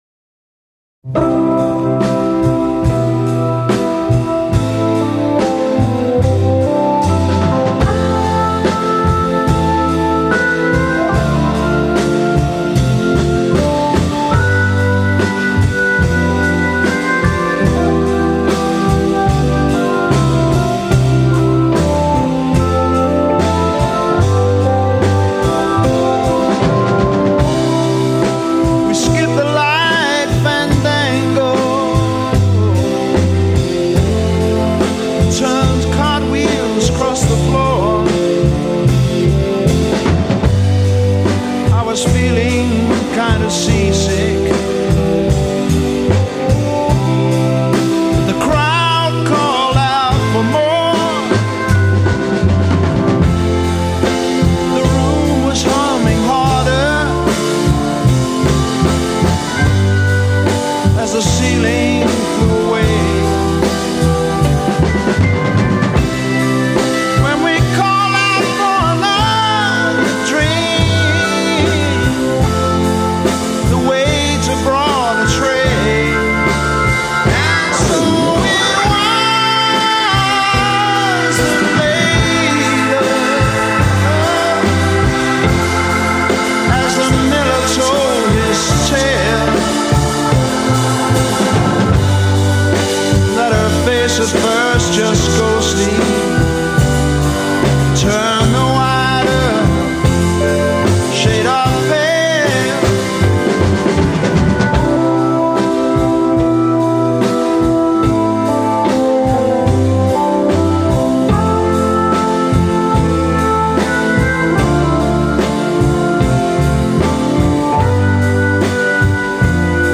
Recorded: Olympic Sound Studios, Barnes, London.
Verse 32 Solo voice over ensemble; organ drops in volume. a
Refrain 16 As above; organ becomes louder. b
Theme 16 Organ plays theme with variation.
Art Rock